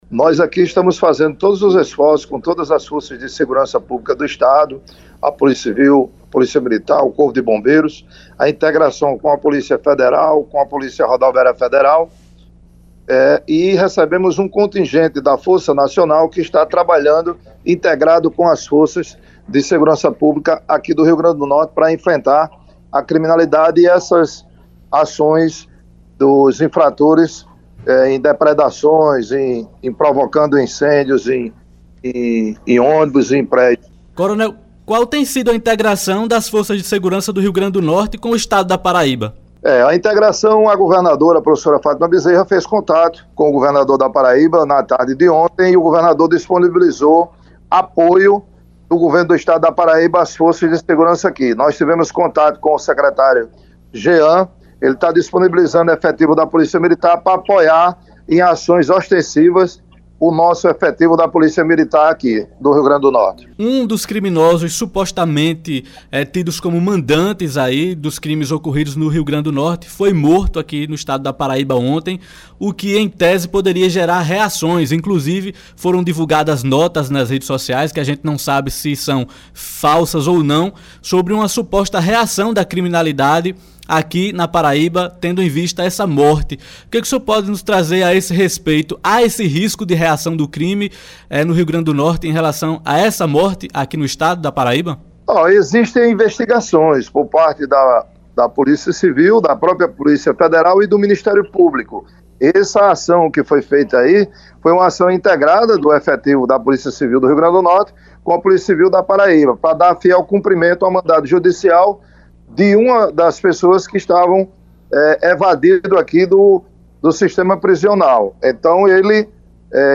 Secretário de segurança do RN solicita apoio de forças da Paraíba para enfrentar criminosos; ouça entrevista exclusiva - Agenda Política